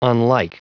Prononciation du mot unlike en anglais (fichier audio)
Prononciation du mot : unlike